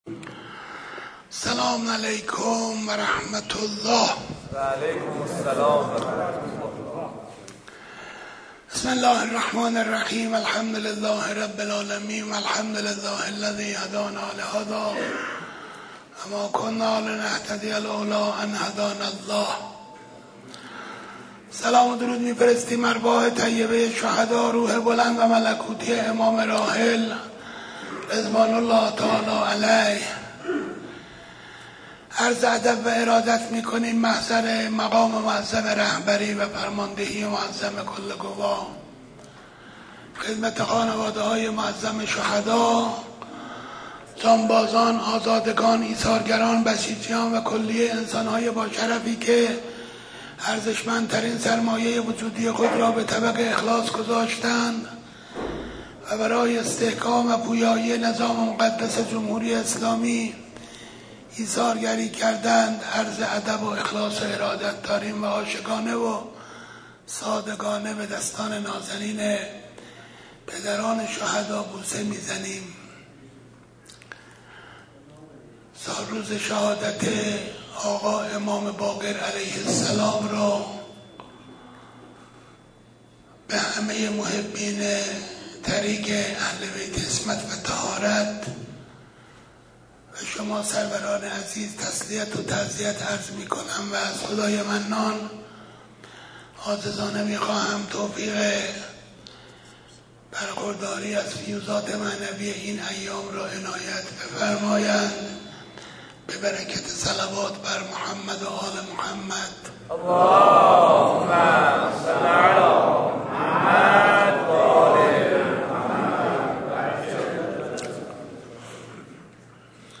سخنرانی
در جلسه ماهانه - ذیحجه 1445